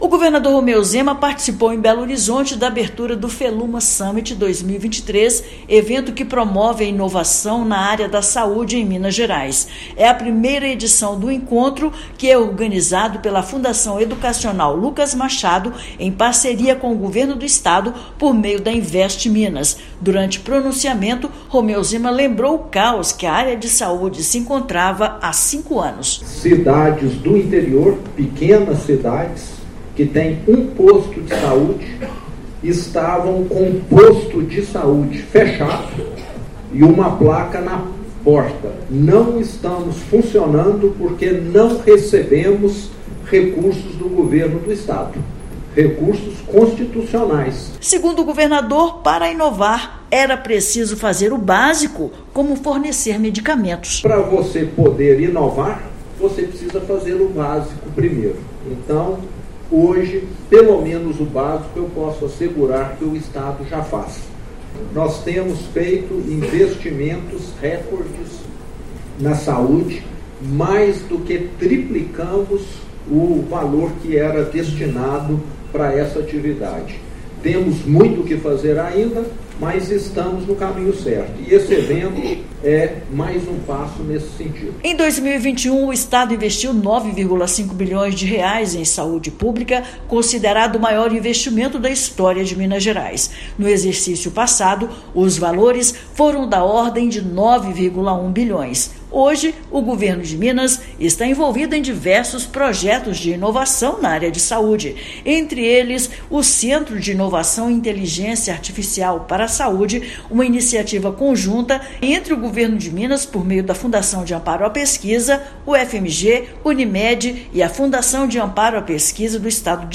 Governador participou da abertura do Feluma Summit 2023, evento que debateu as novas fronteiras tecnológicas. Ouça matéria de rádio.